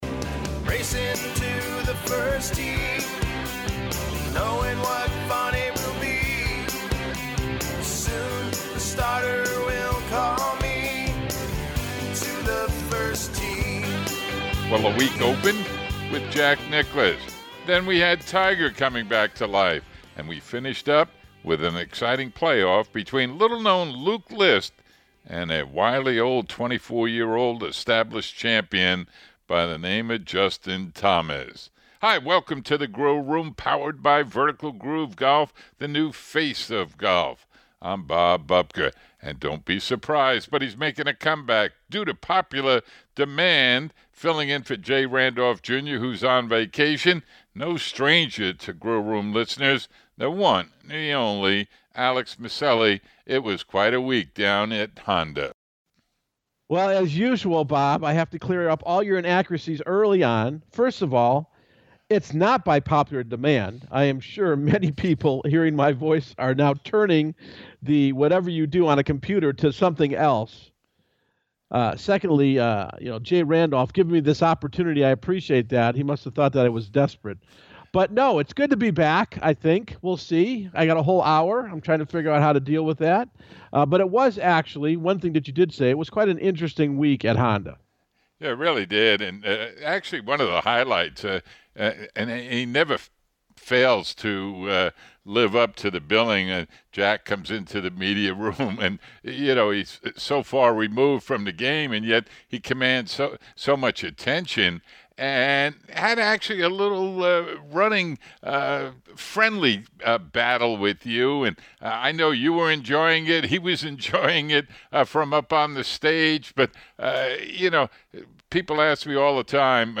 is the Feature Interview.